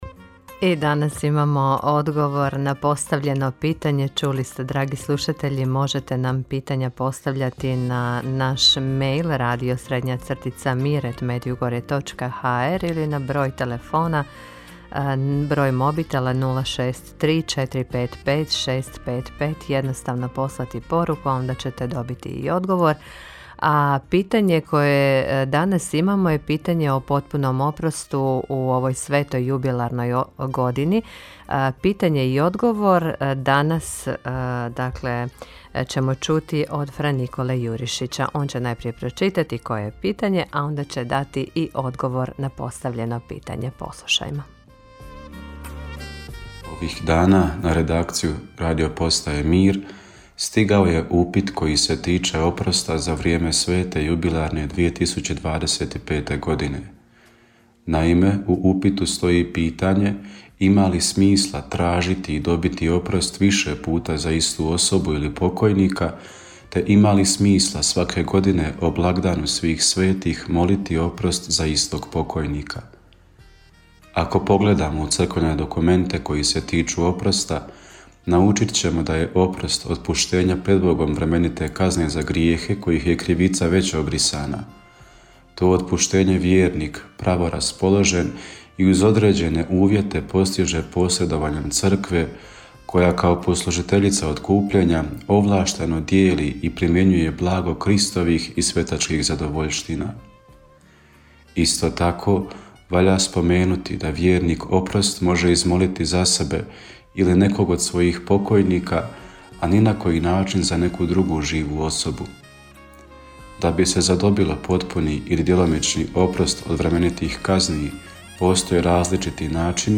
Rubrika ‘Pitajte svećenika’ u programu Radiopostaje Mir Međugorje je ponedjeljkom od 8 sati i 20 minuta, te u reprizi ponedjeljkom navečer u 20 sati. U njoj na pitanja slušatelja odgovaraju svećenici, suradnici Radiopostaje Mir Međugorje.